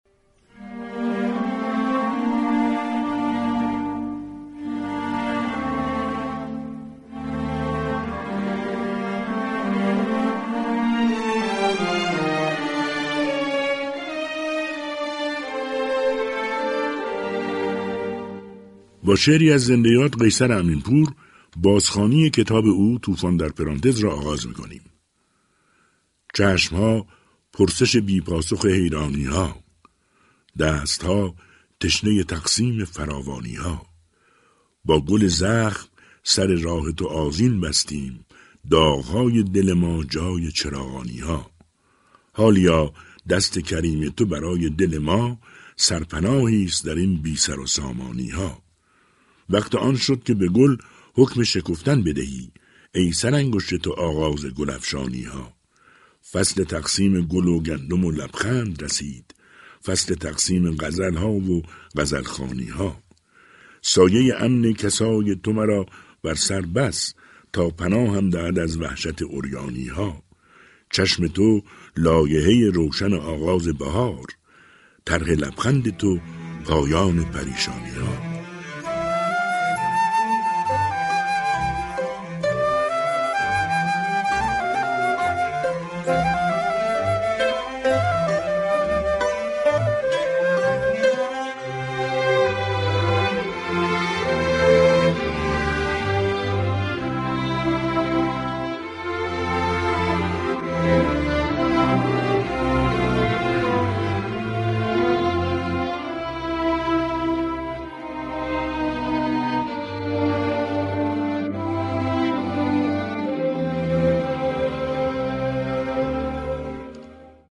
شعرهای قیصر امین‌پور با صدای بهروز رضوی+فایل صوتی
كتاب «توفان در پرانتز» اثر زنده‌یاد قیصر امین‌پور در برنامه «كتاب شب» رادیو تهران با صدای بهروز رضوی بازخوانی می‌شود.